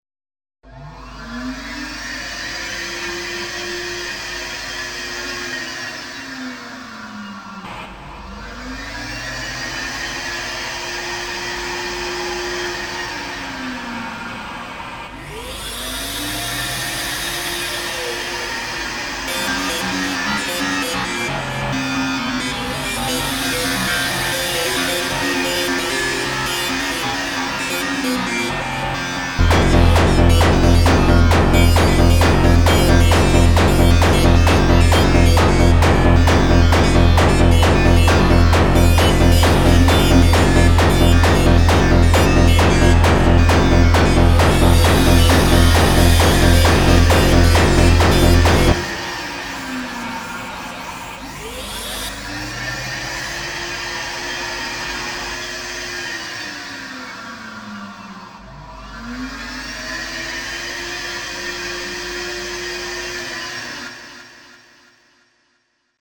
Did you get that I only used a real hoover for the recording a few post above (post 61)? (Octatrack recording) Kick with my hoover too.
The best hoover sound!